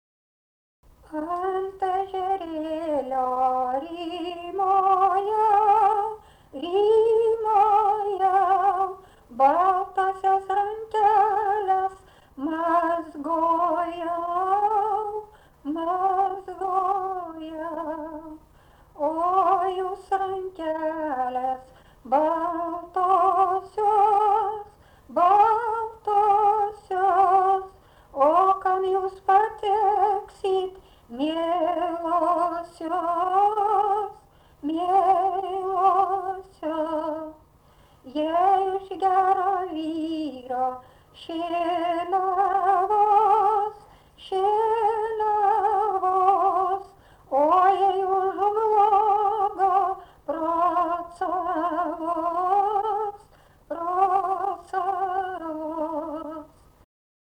daina, vestuvių
Erdvinė aprėptis Suvainiai
Atlikimo pubūdis vokalinis